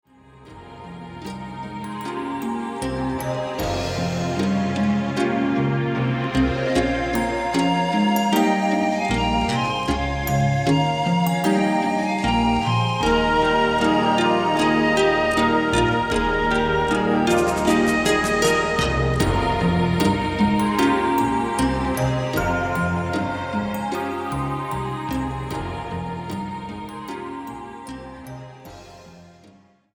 re-mastered, überarbeiteter Sound, Bonus Songs, Bonus Videos
Recorded spring 1986 at the Sinus Studios, Bern Switzerland